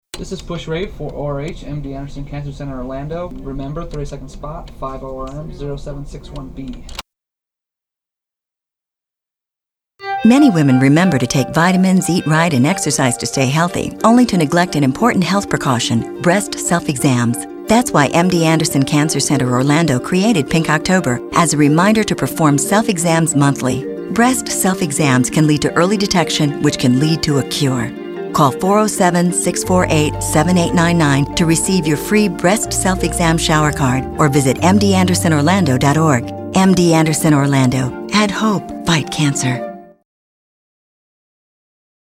30 Second Radio Spot